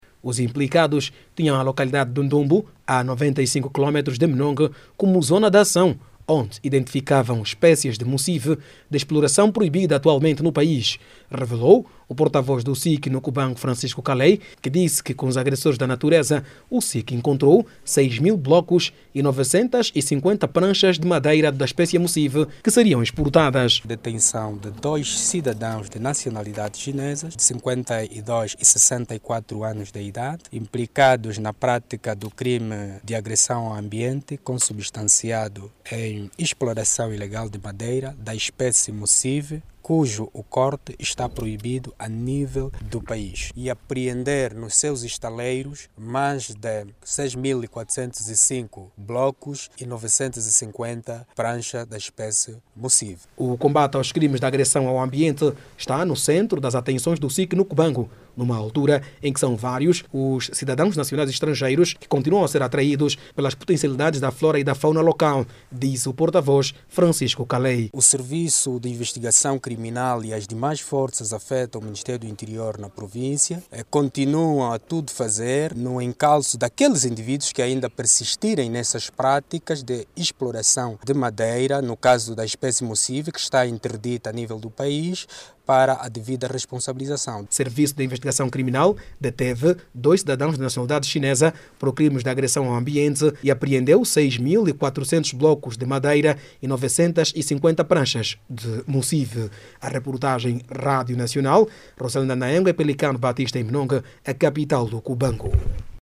O Serviço de Investigação Criminal, na província do Cubango, deteve dois cidadãos de nacionalidade chinesa por pratica de exploração ilegal de madeira. Com implicados foram encontrados, mais de novecentas pranchas de madeira da espécie Mussivi cuja a exploração está proibida a nível do país. Clique no áudio abaixo e ouça a reportagem